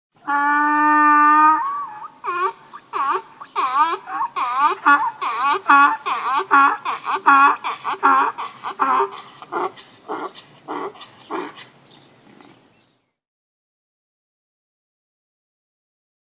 جلوه های صوتی
دانلود صدای خر ناراحت از ساعد نیوز با لینک مستقیم و کیفیت بالا
برچسب: دانلود آهنگ های افکت صوتی انسان و موجودات زنده دانلود آلبوم صدای خر و الاغ از افکت صوتی انسان و موجودات زنده